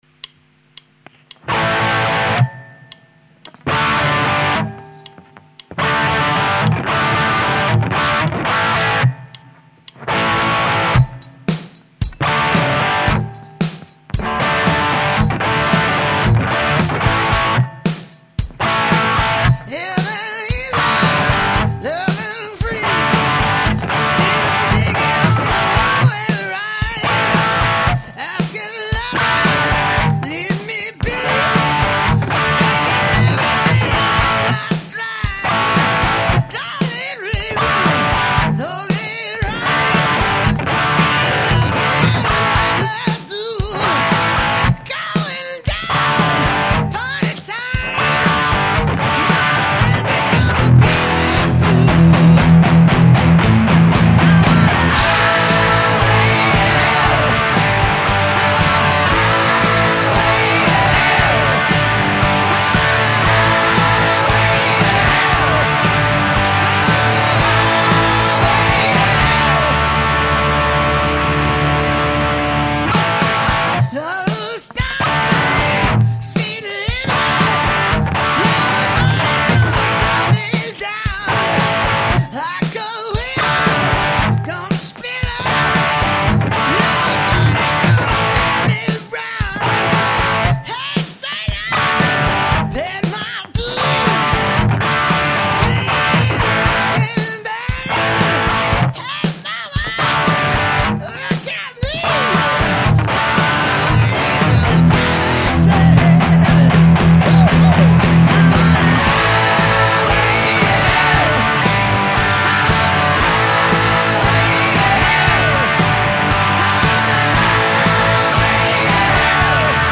3- LTD KH-602 (EMG 81 pos chevalet), gain aux 3/4, isf à gauche :